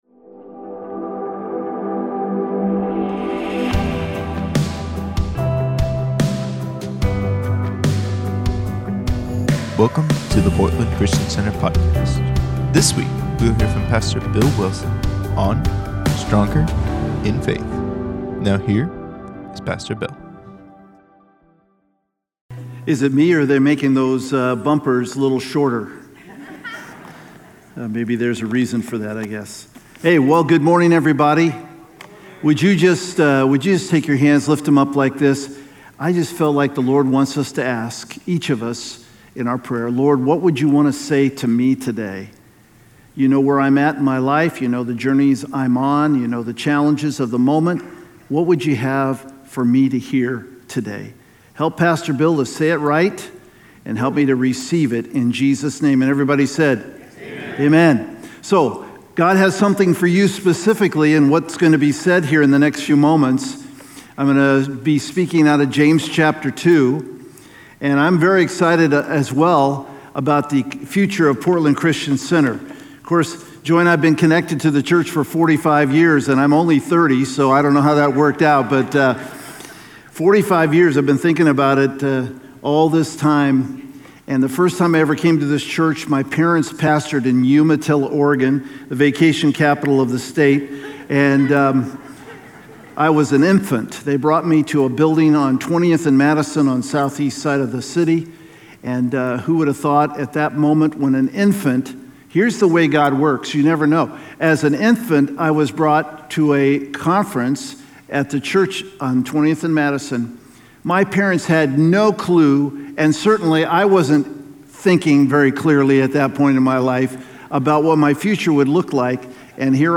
Sunday Messages from Portland Christian Center Stronger: In Faith May 15 2022 | 00:55:00 1x 00:00 / 00:55:00 Subscribe Share Spotify RSS Feed Share Link Embed